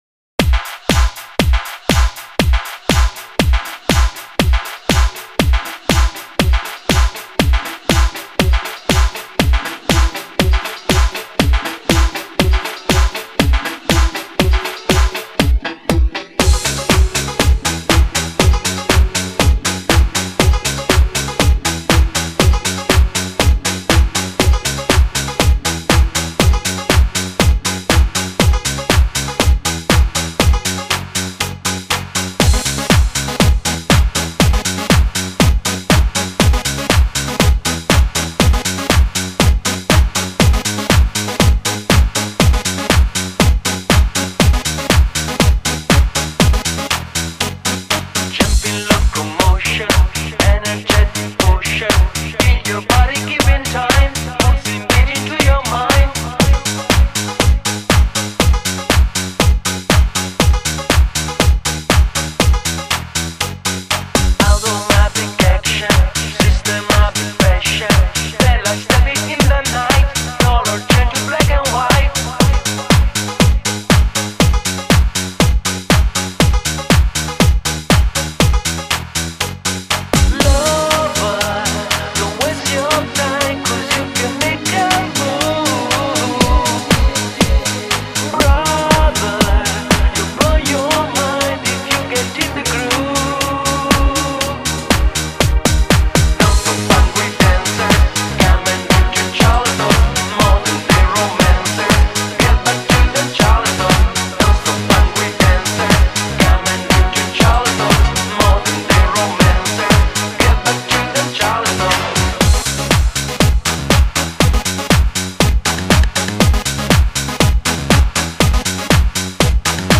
Electronic Italo-Disco 1986